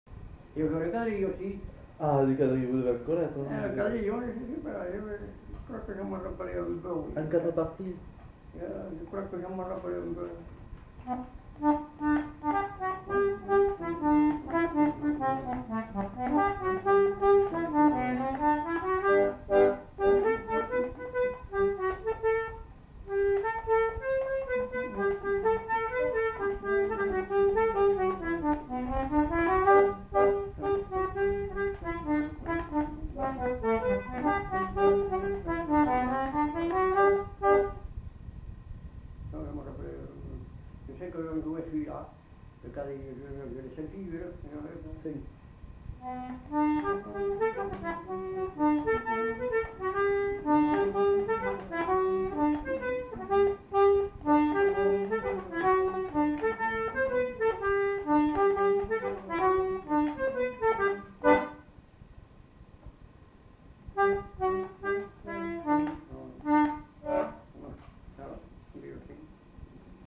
Aire culturelle : Marmandais gascon
Lieu : Mas-d'Agenais (Le)
Genre : morceau instrumental
Instrument de musique : accordéon diatonique
Danse : quadrille